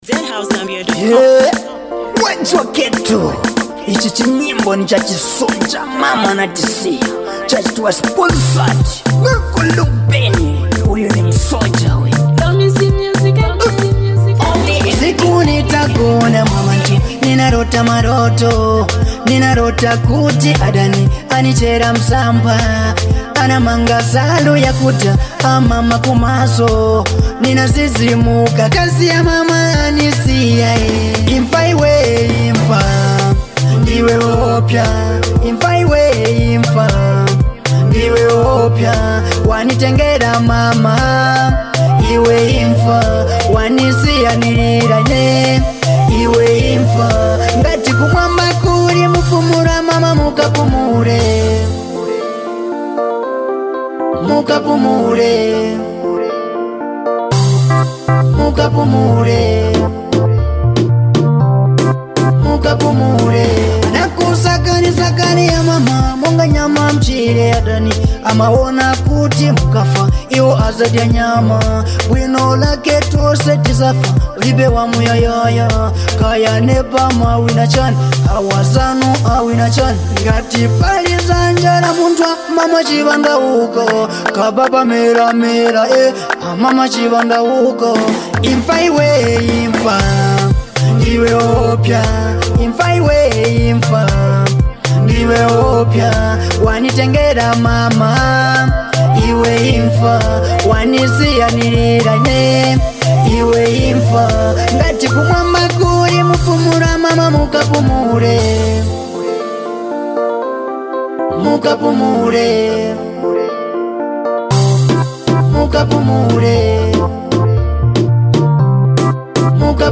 a deep and emotional track